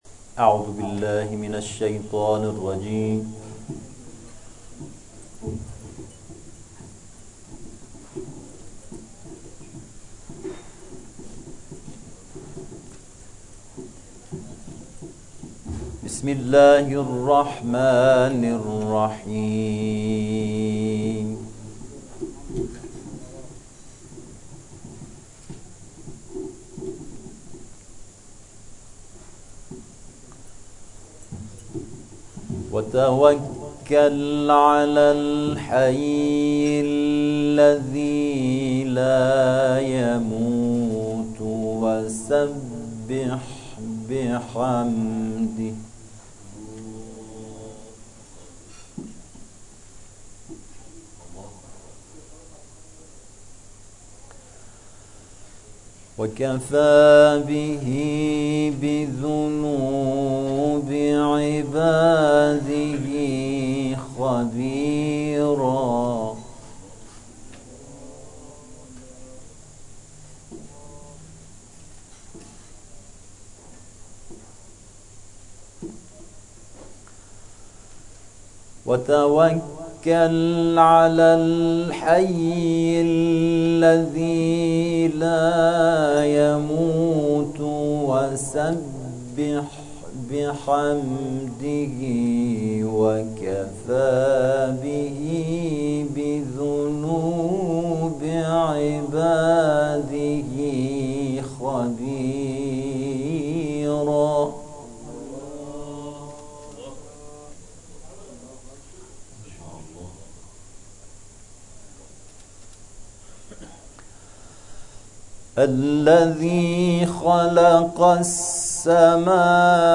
این جلسه که قدمتی ۴۵ ساله دارد، از همان ابتدای تاسیس در ماه مبارک رمضان 30 شب مراسم جزءخوانی برپا کرده است.